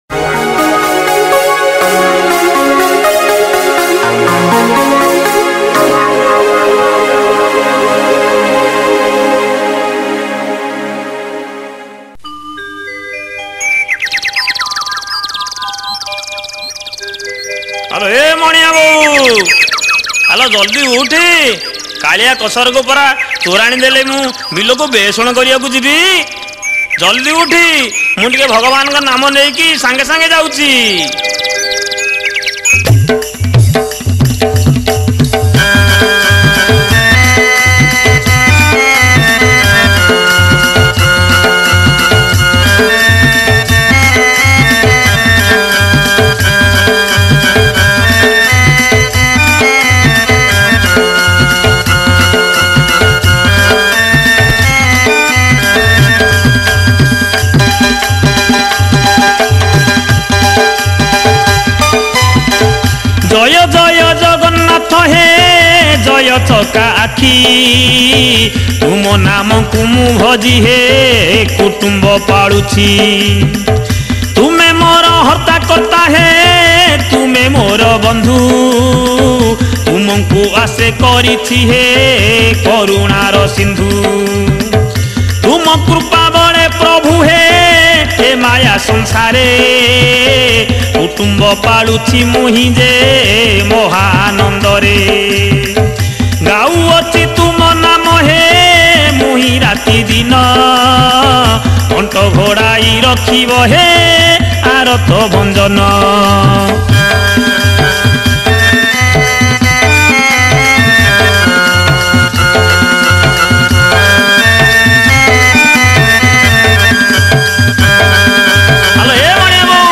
Category : HALUA GITA